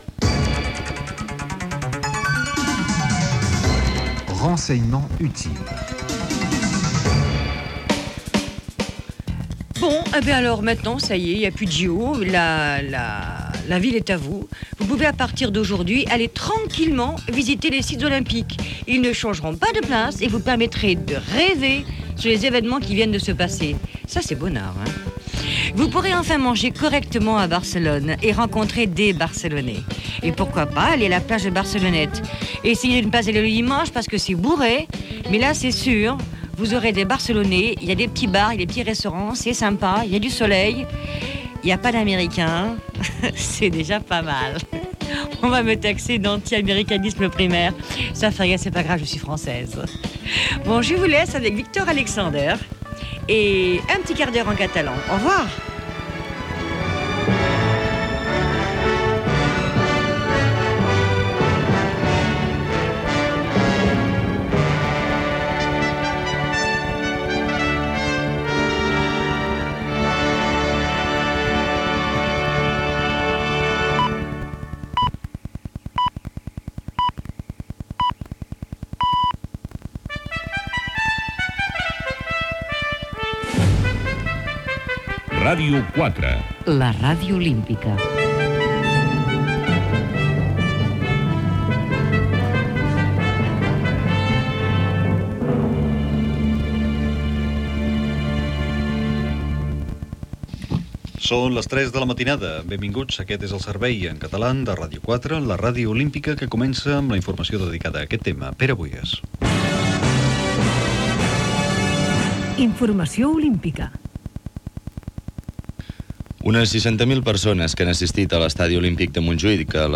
Final de l'informatiu en francès, indicatiu i informatiu en català: cerimònia de cloenda.
Informatiu
FM